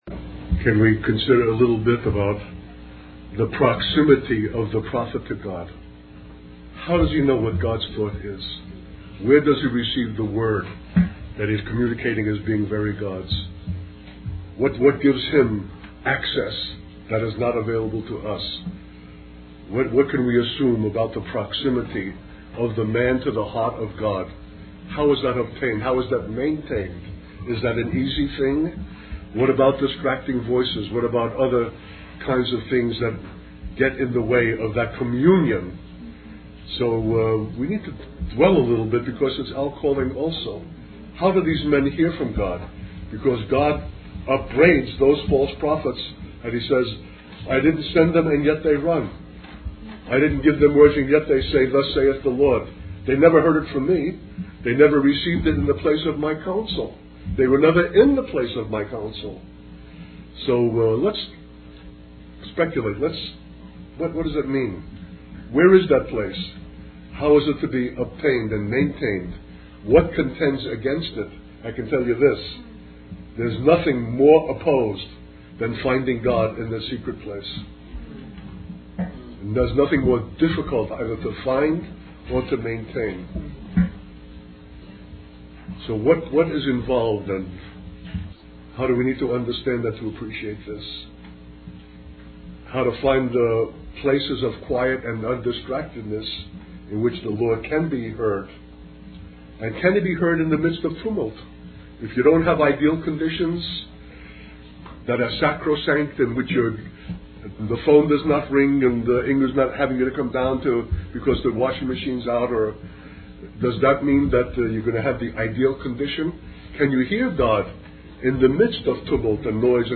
In this sermon, the speaker addresses the issue of applying machine-age methods to our relationship with God. He highlights the negative consequences of this approach, such as shallow lives, hollow religious philosophies, and the glorification of men. The speaker emphasizes the importance of embracing reality, even when it is painful, as it is the foundation of everything and allows us to stay connected with God.